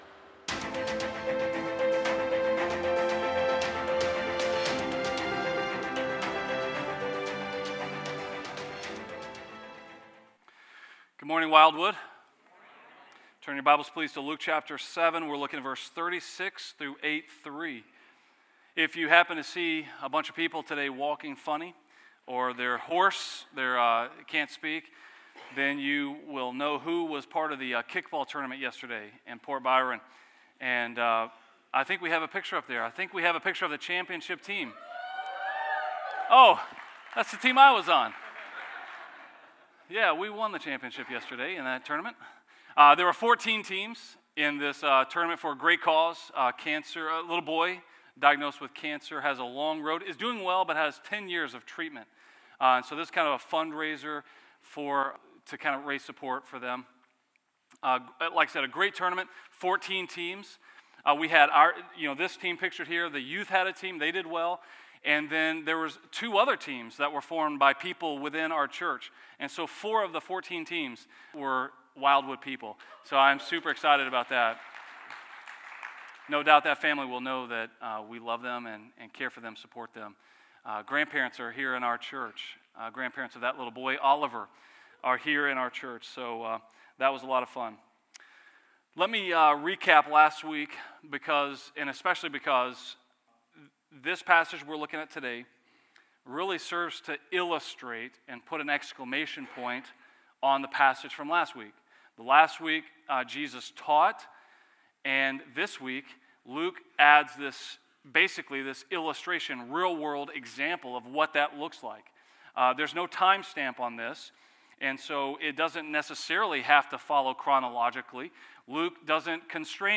A message from the series "Behold the Glory."